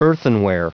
Prononciation du mot earthenware en anglais (fichier audio)
Prononciation du mot : earthenware